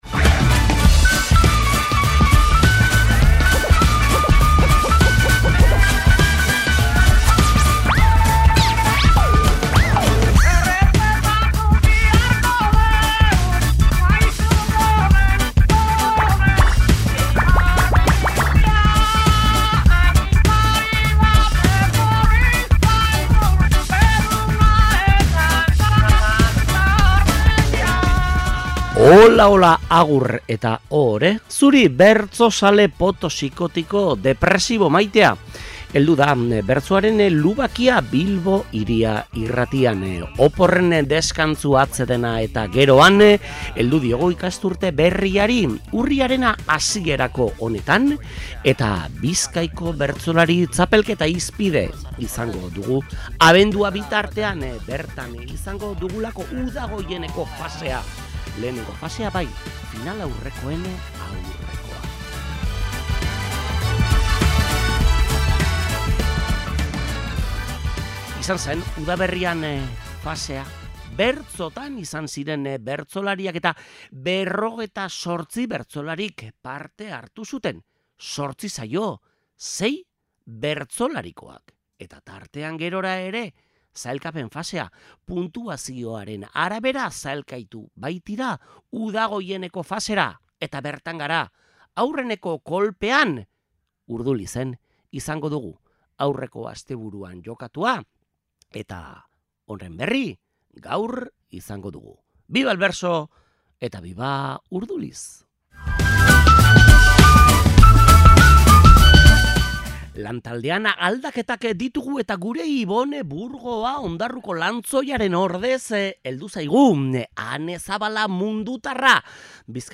POTTO: Bizkaiko bertso txapelketa Urdulizen jokatutakoa
Bizkaiko Udagoieneko fasearen lehenengo saioa izan genuen Urdulizen, eta bertsoen aukeraketa diktatoriala duzue entzungai gaurko saioan.